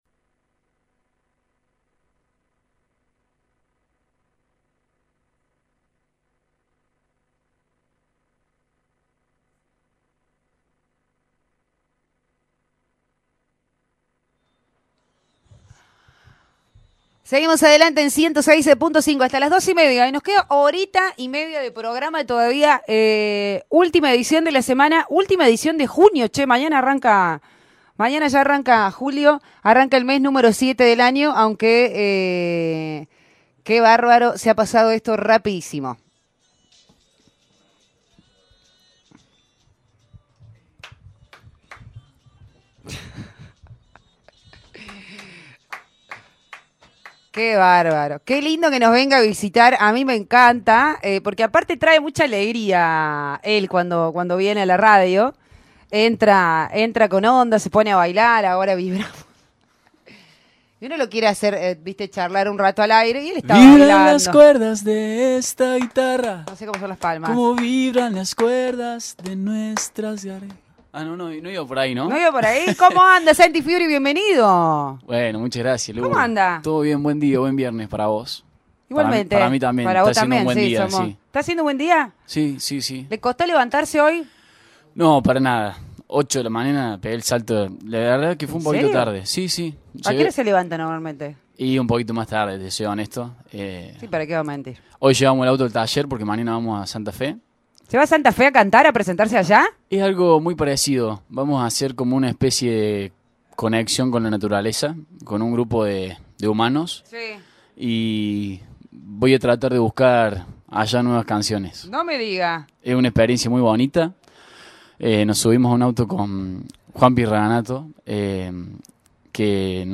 visitó los estudios de la radio